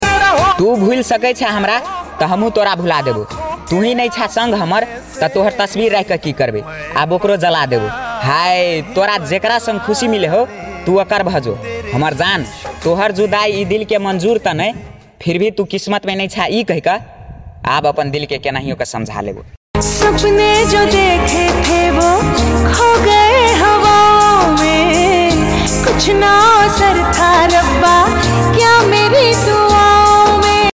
sad song 😔❤‍🩹 sad video sound effects free download